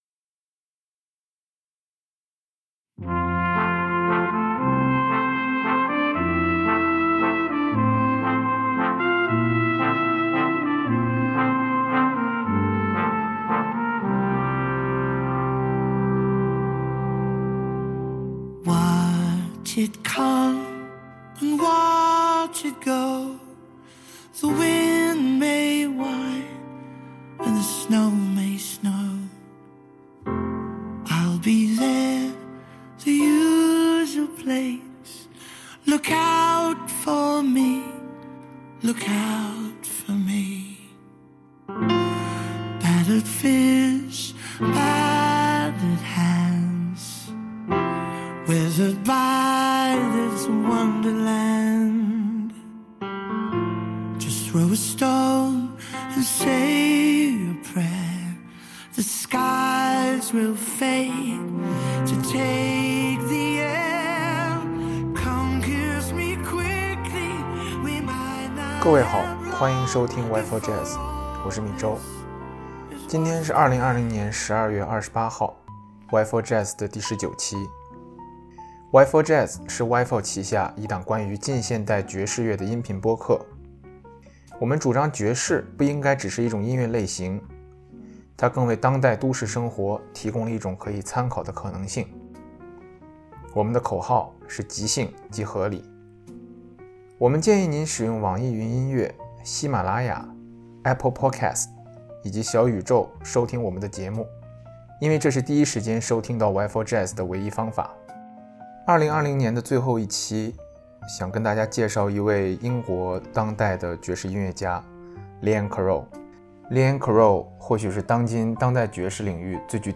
E19: Liane Carroll | 英国国宝爵士烟嗓